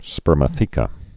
(spûrmə-thēkə)